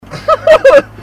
Laugh 0
Category: Comedians   Right: Both Personal and Commercial